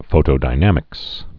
(fōtō-dī-nămĭks)